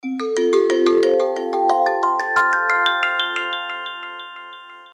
• Качество: 320, Stereo
мелодичные
без слов
Красивая мелодия